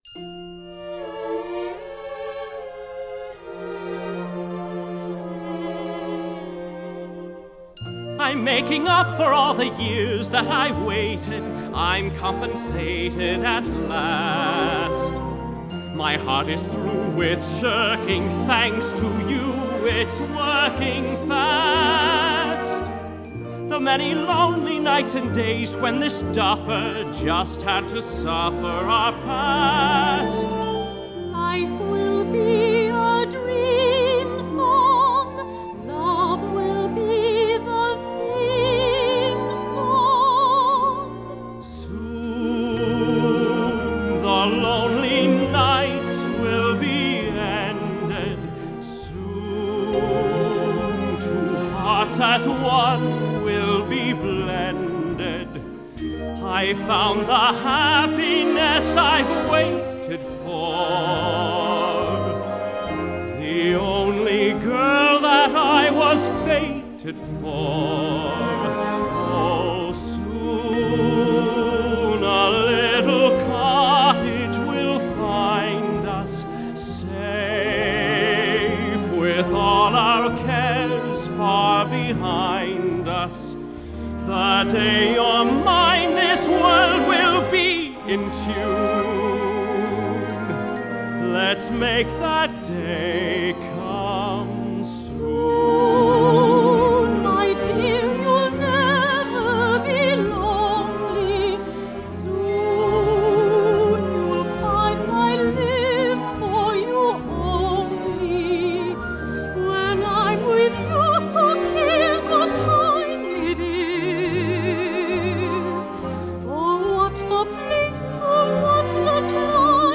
2011 Studio Cast Recording